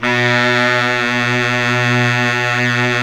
Index of /90_sSampleCDs/Roland LCDP07 Super Sax/SAX_Sax Ensemble/SAX_Sax Sect Ens
SAX 2 BARI0L.wav